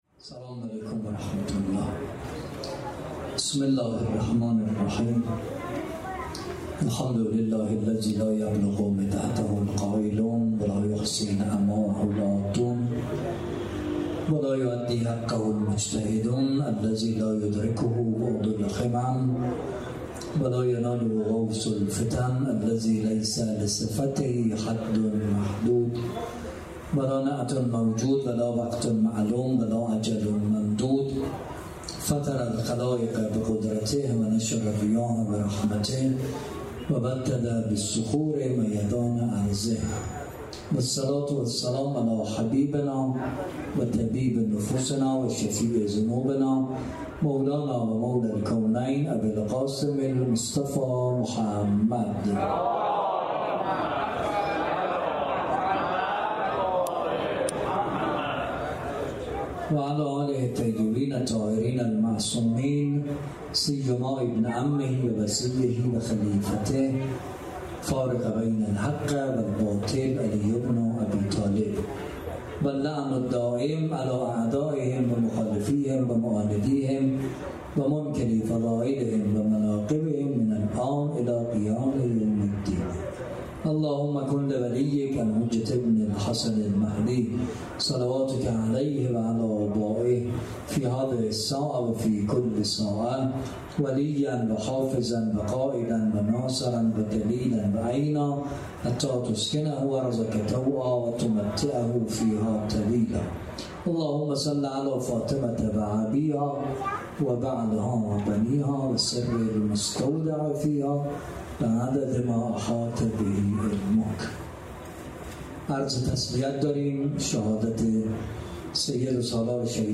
مراسم عزاداری دهه دوم محرم الحرام ۱۴۴۷_ قزوین